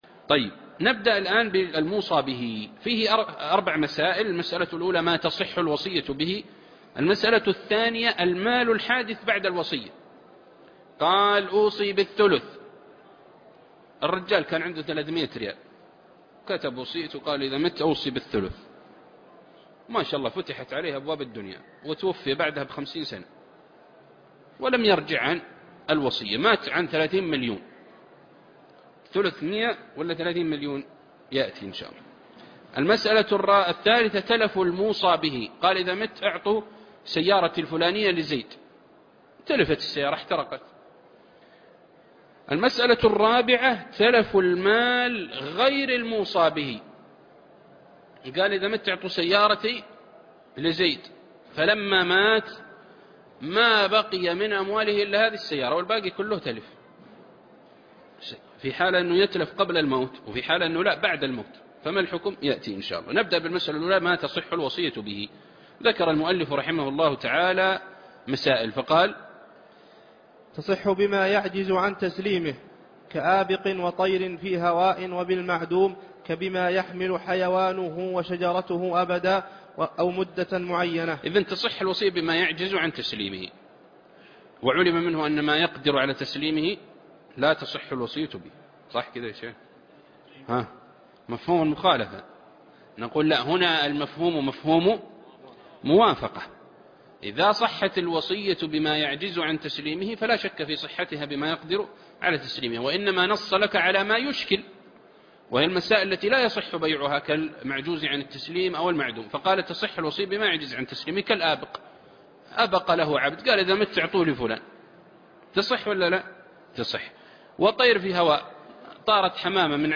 الدرس 103- شرح زاد المستقنع ( باب الموصى به )